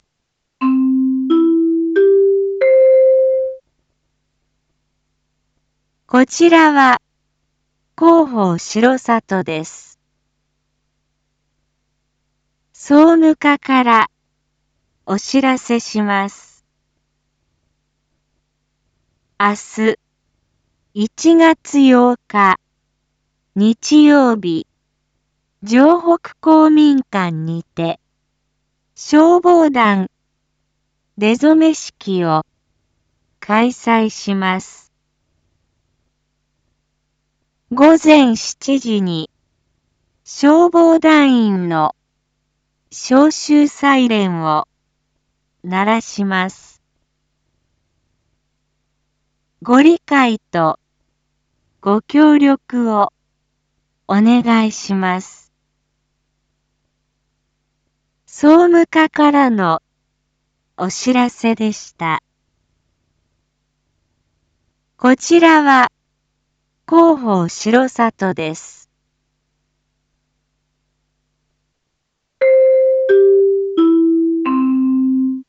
一般放送情報